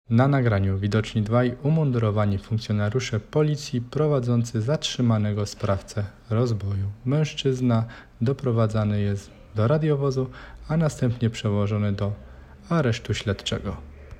Nagranie audio Audiodeskrypcja.m4a